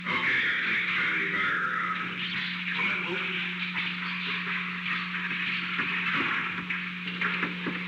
Secret White House Tapes
Conversation No. 607-9
Location: Oval Office